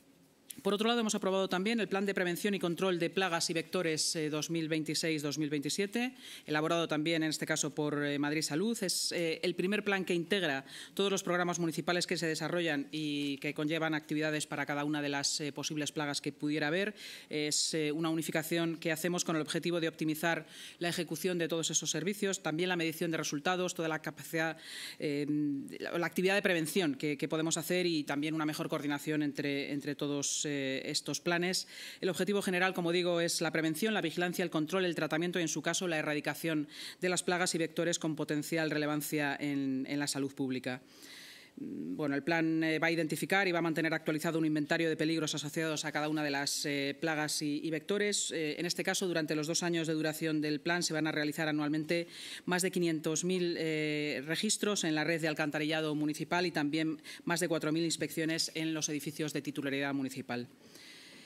Esta unificación en un único plan tiene el objetivo de optimizar la ejecución de los servicios y la medición de sus resultados, garantizando así una mejor coordinación en todos los aspectos, ha destacado la vicealcaldesa, delegada de Seguridad y Emergencias y portavoz municipal, Inma Sanz, en la rueda de prensa